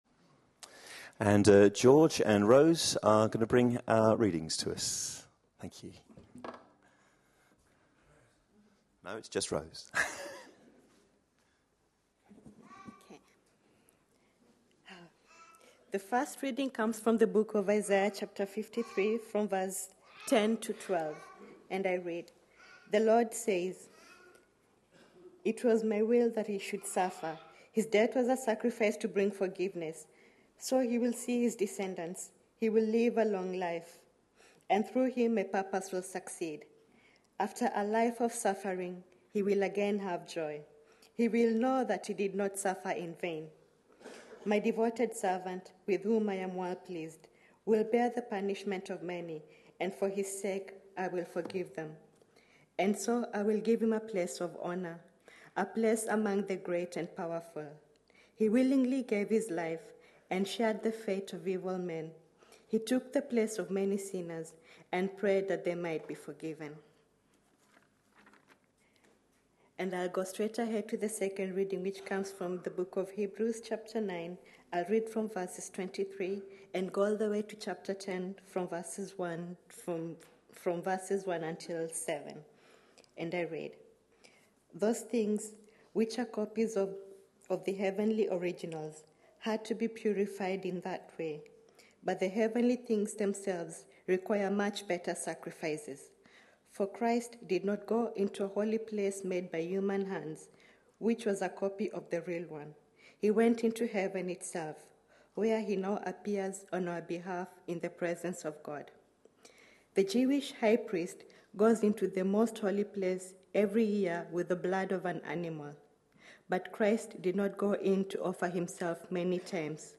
A sermon preached on 13th March, 2016, as part of our Lent 2016. series.